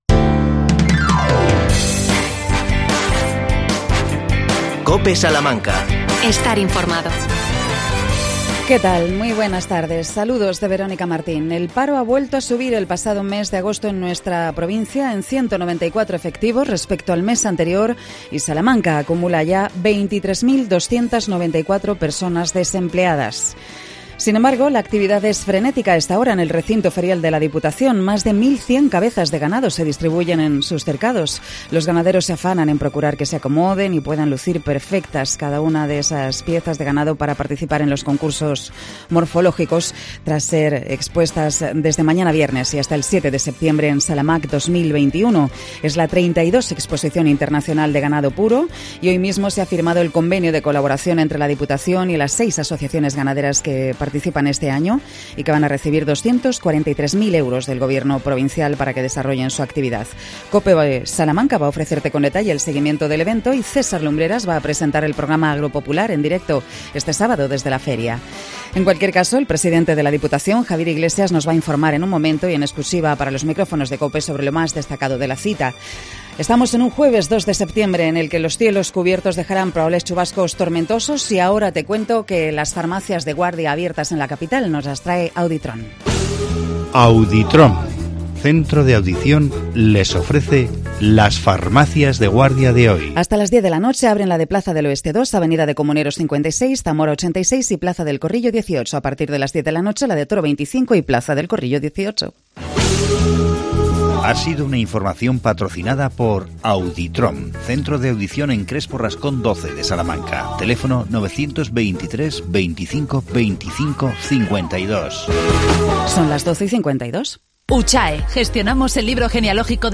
AUDIO: Javier Iglesias, presidente de la Diputación Provincial informa sobre los detalles de SALAMAQ 2021